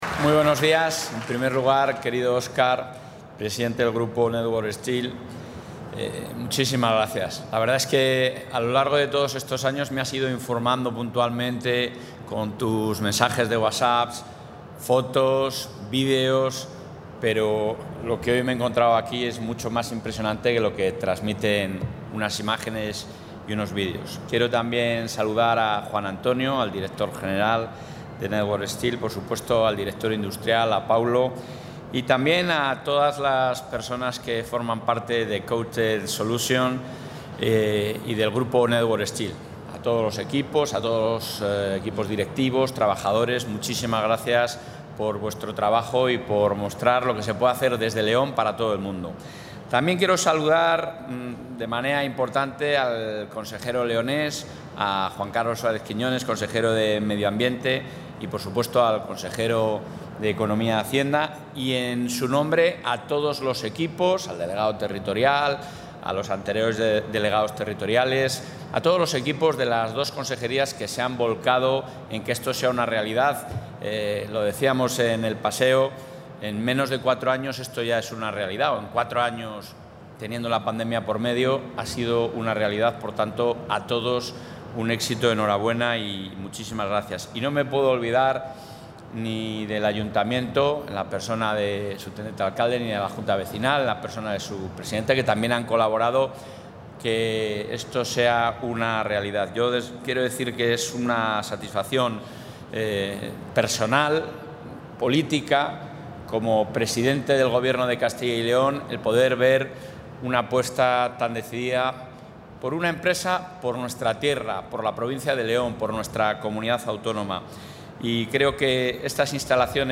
Intervención del presidente de la Junta.
En su visita de hoy a las instalaciones de Network Steel en Villadangos del Páramo, el presidente de la Junta de Castilla y León, Alfonso Fernández Mañueco, ha destacado que esta empresa, líder y referente del sector siderúrgico tanto a nivel nacional como europeo, demuestra a través de su proyecto industrial un gran compromiso con León y el conjunto de la Comunidad.